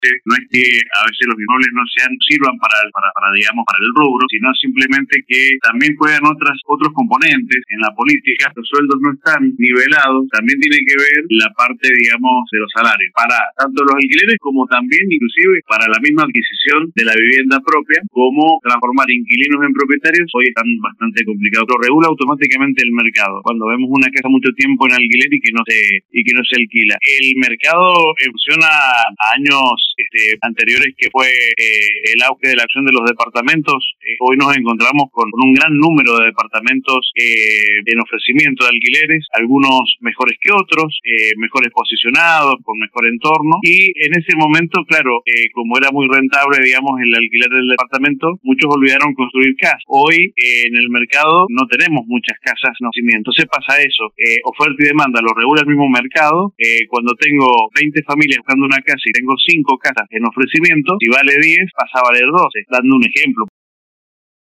En una entrevista con LV18